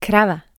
krava.wav